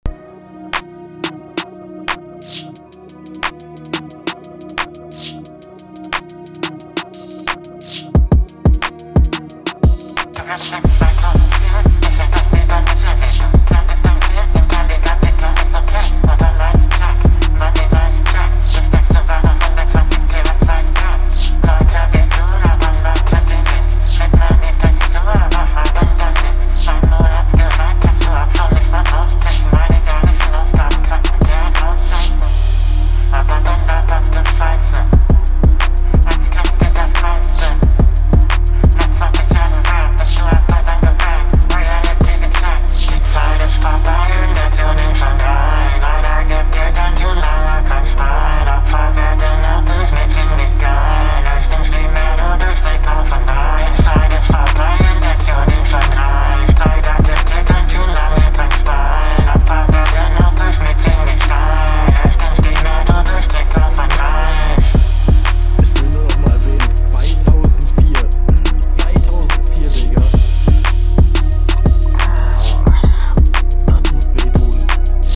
Bro dein Flow auf dem Beat kommt gut, wenn man nur was verstehen könnte ich …
😂 Ich verstehe GAR NICHTS.